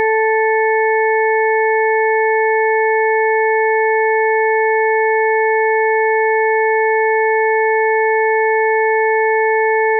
When suppressing the 1320 Hz harmonic, the four remaining tones fuse together in a perfect octave consonance, but with a different timbre (Figure 1b). Moreover, as the energy from the cancelled harmonic is not present, perceived loudness has reduced but just one stream is still clearly perceived.
Figure 1b: Chord 440 without 1320 Hz tone